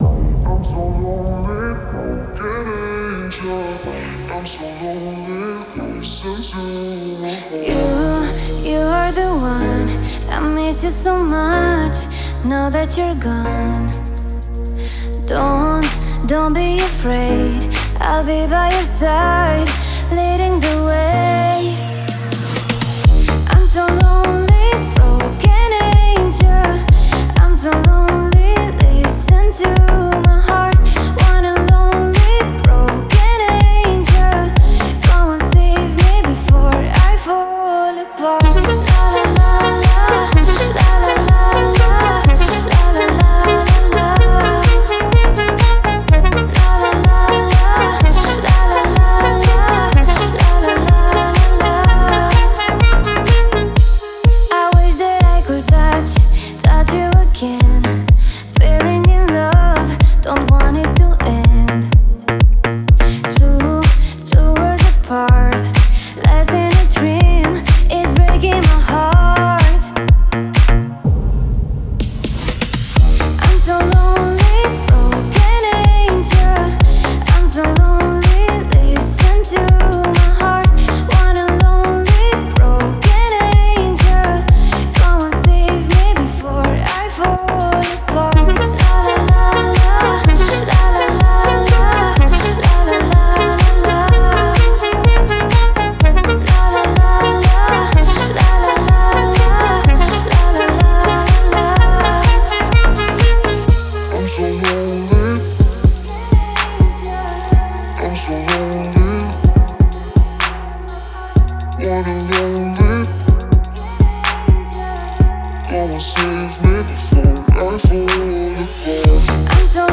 это эмоциональная электронная композиция в жанре EDM